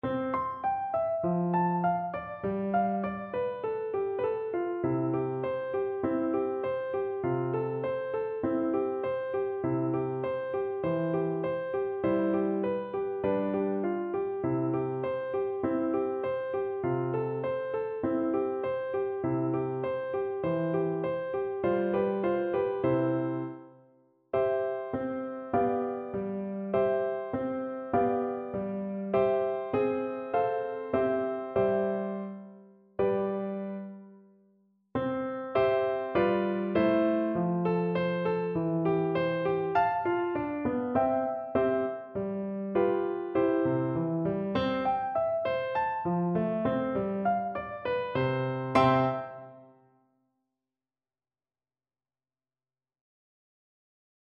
Flute version
Play (or use space bar on your keyboard) Pause Music Playalong - Piano Accompaniment Playalong Band Accompaniment not yet available transpose reset tempo print settings full screen
Flute
C major (Sounding Pitch) (View more C major Music for Flute )
Moderato
2/2 (View more 2/2 Music)
Traditional (View more Traditional Flute Music)